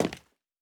PavementTiles_Mono_05.wav